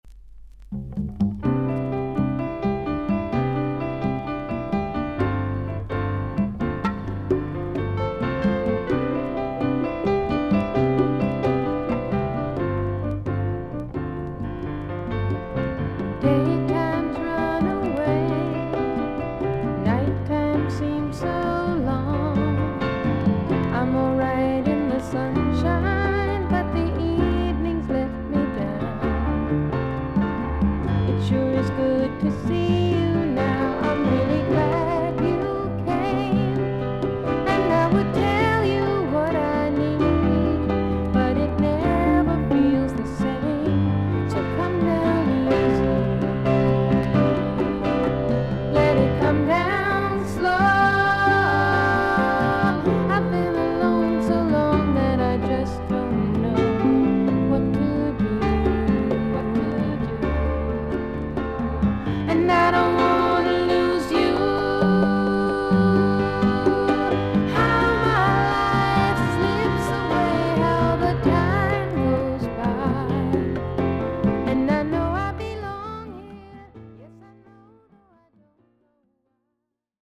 女性シンガー/ソングライター。
VG++〜VG+ 少々軽いパチノイズの箇所あり。クリアな音です。